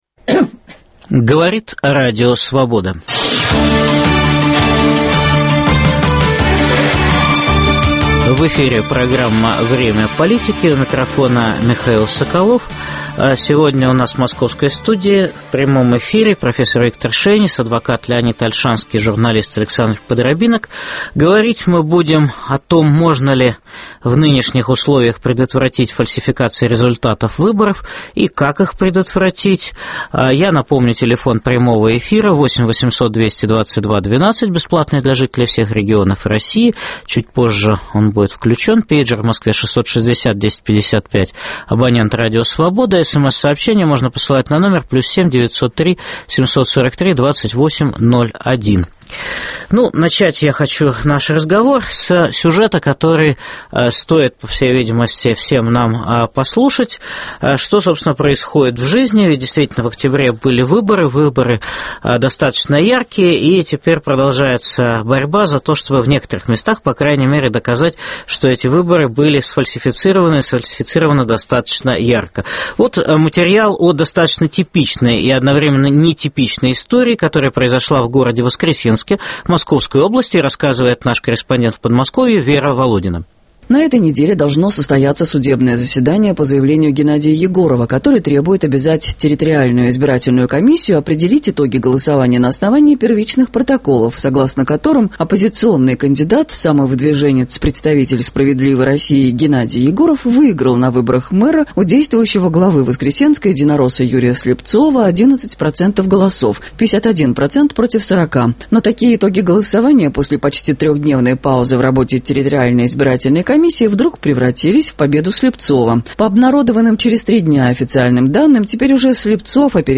Тема программы: можно ли предотвратить фальсификации результатов выборов? В прямом эфире дискутируют